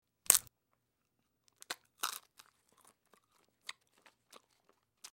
Chewing
Break Shell And Chew With Lip Smack And Close Bite, X4